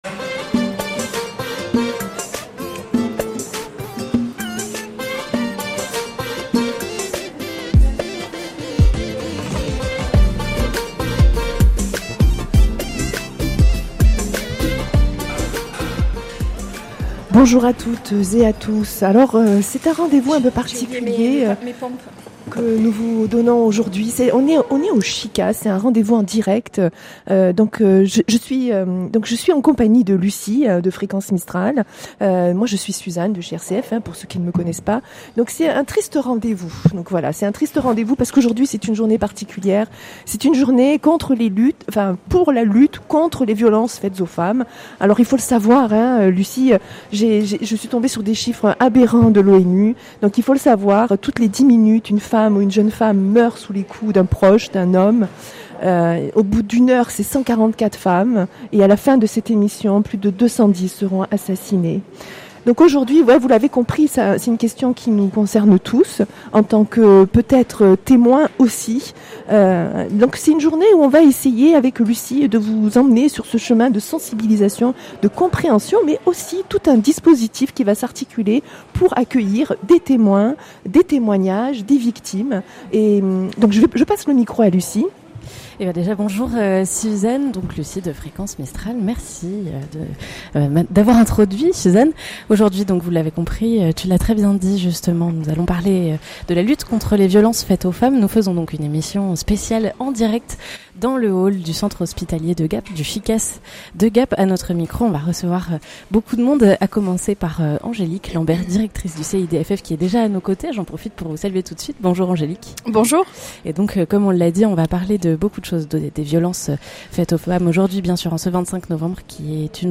Pour se joindre à cette journée internationale de lutte, RCF Alpes Provence et Fréquence Mistral Gap ont animé un plateau radio, en direct depuis le hall du CHICAS (Centre Hospitalier Intercommunal des Alpes du Sud) de Gap et en partenariat avec le CIDFF 05 .